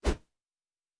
Whoosh 09.wav